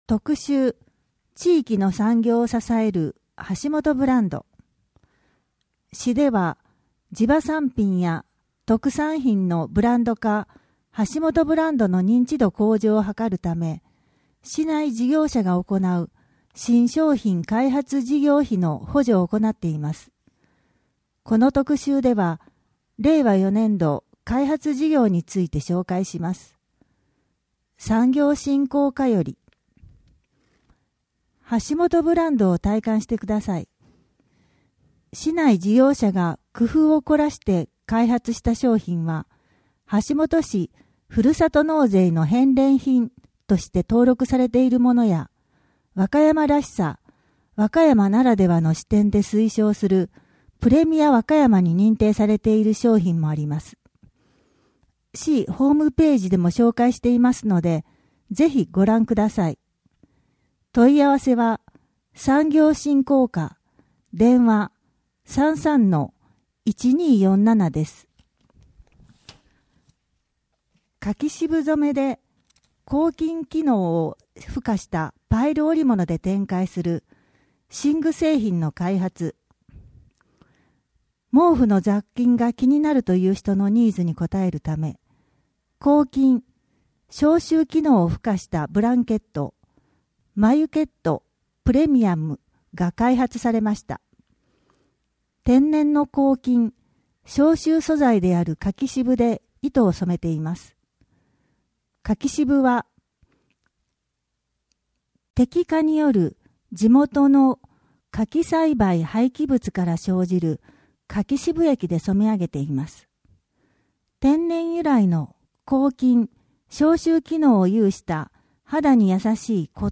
WEB版　声の広報 2023年11月号